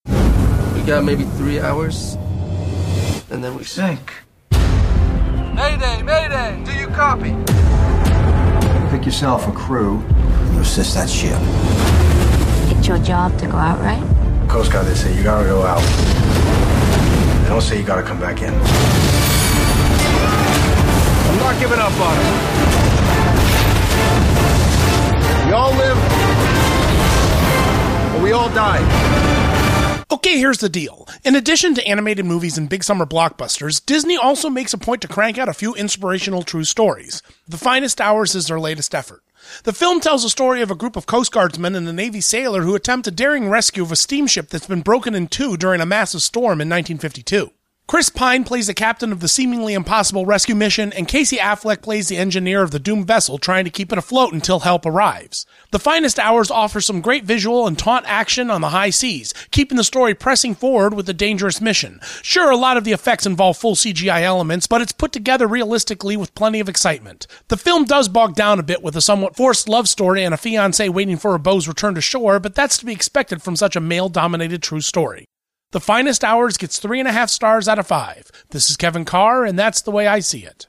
radio review